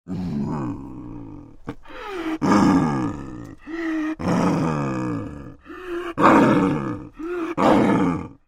Звуки снежного барса
Рычание снежного барса